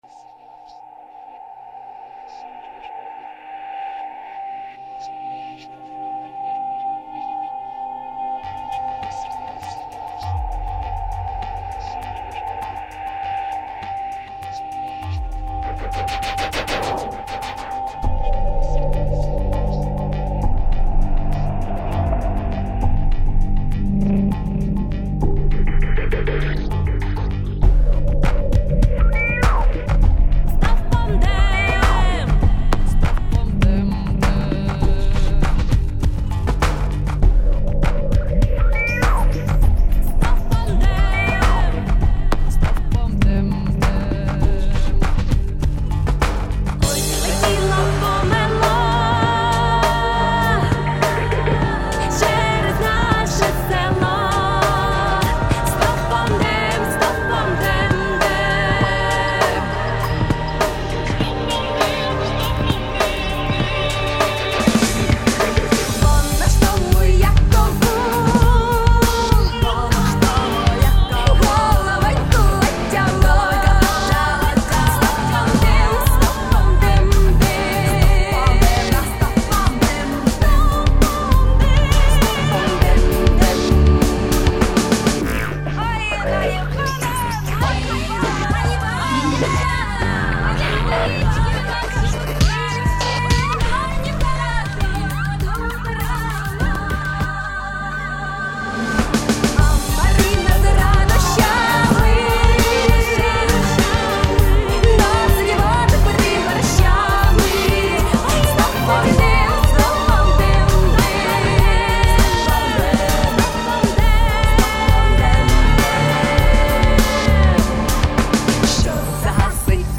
Стиль - нео-фолк.
Понравилось как записан вокал, барабаны неплохо звучат с перкуссией...
Эдакое интро.
Не понравились скрипки =)
а ты слышишь сколько там слоёв + 6 линий вокала.
Здесь гитара выполняет роль виоланчеля, не плотнее.
Очень понравился мягкий звук баса, именно под тему!